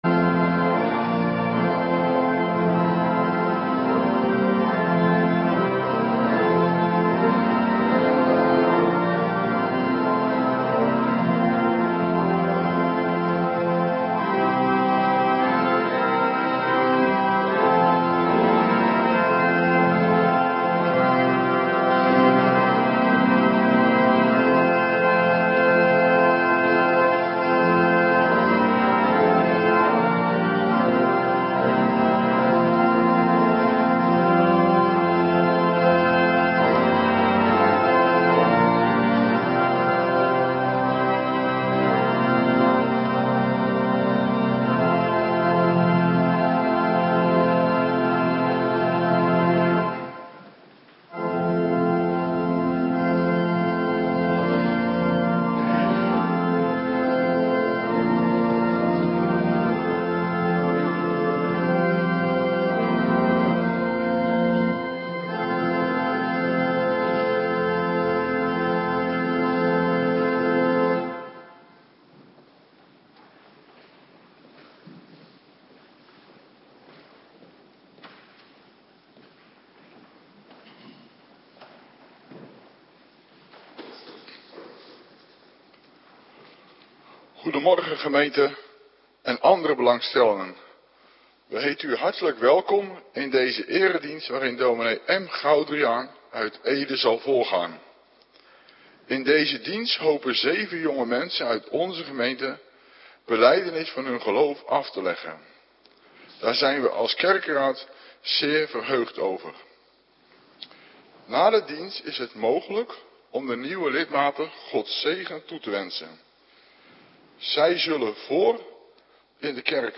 Locatie: Hervormde Gemeente Waarder